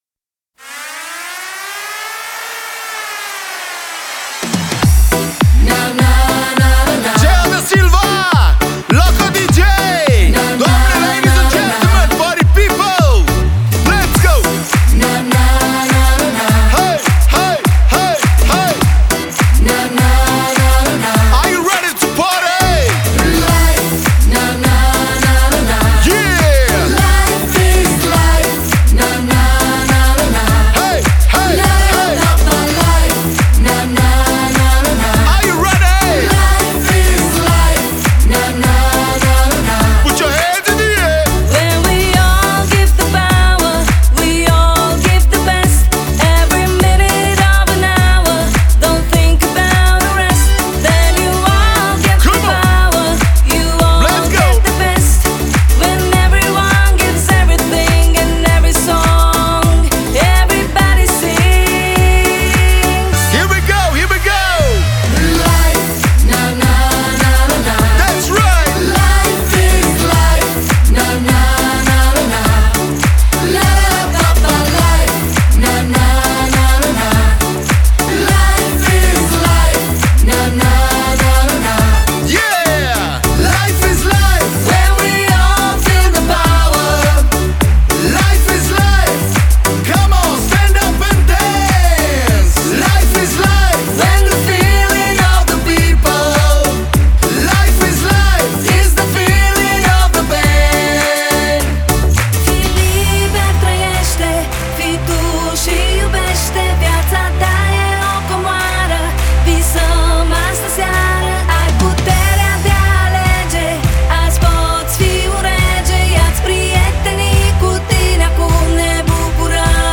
это зажигательный трек в жанре EDM